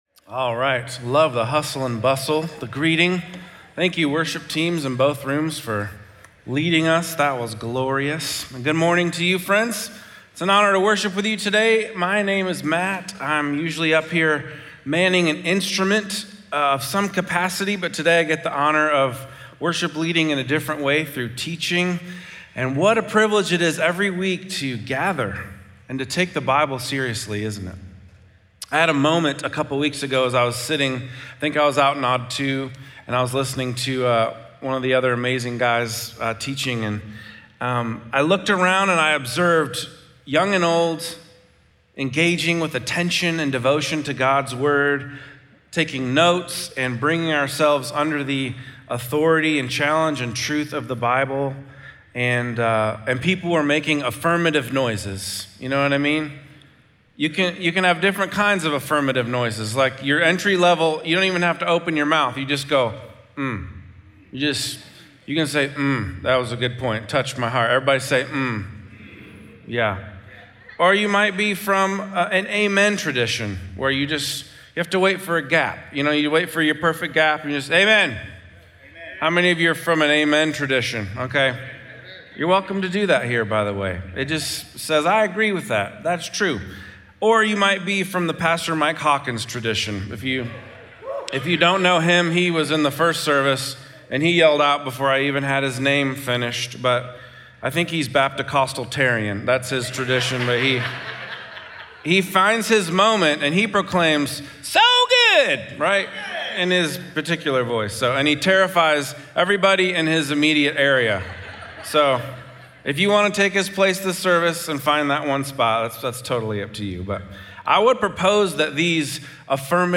Psalm 51 Audio Sermon Notes (PDF) Ask a Question SERMON SUMMARY Scripture: Psalm 51 This part of the story stinks.